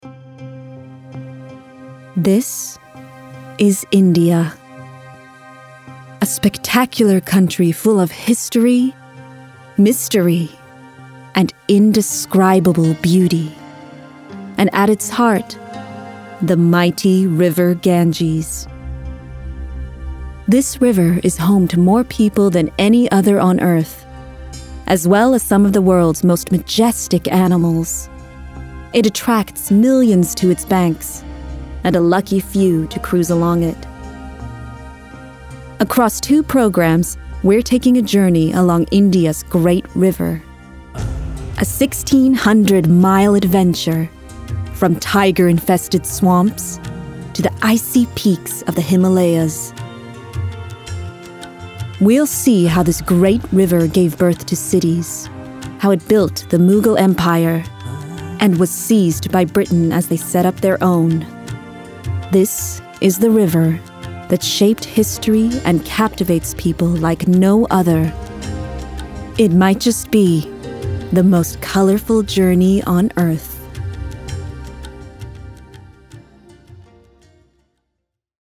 Documentary Showreel
Female
Smooth
Cool